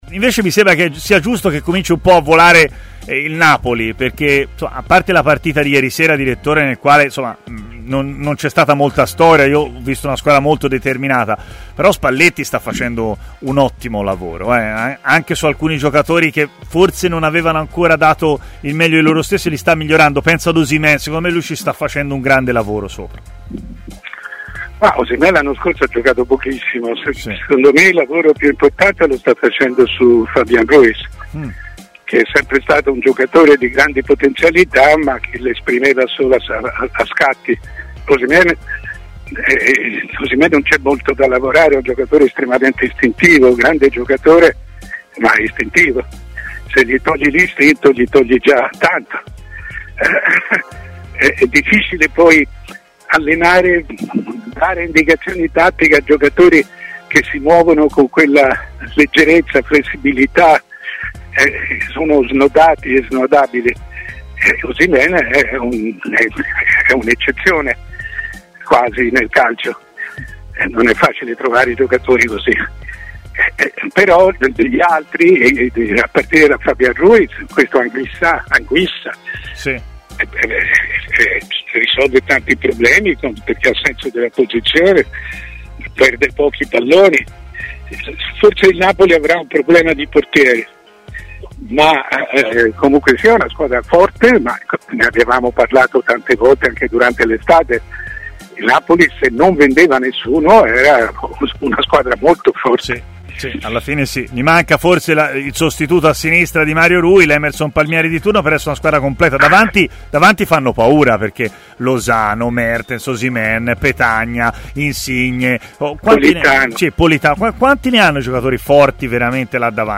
Ospite di Stadio Aperto, trasmissione pomeridiana di TMW Radio, Mario Sconcerti si è espresso su diversi temi dell'attualità calcistica